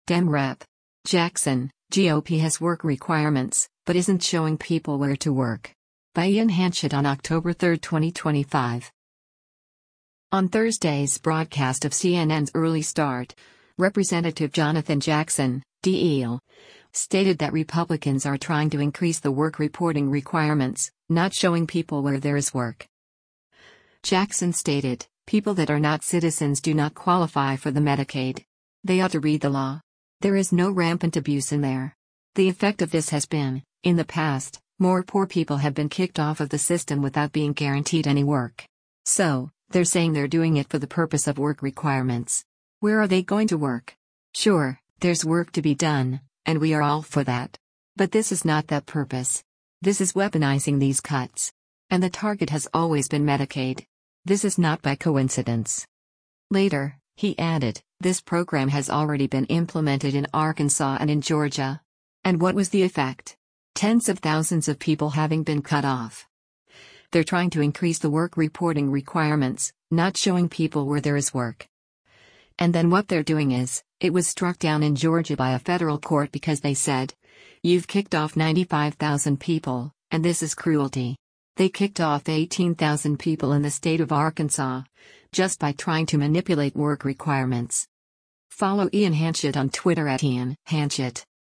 On Thursday’s broadcast of CNN’s “Early Start,” Rep. Jonathan Jackson (D-IL) stated that Republicans are “trying to increase the work reporting requirements, not showing people where there is work.”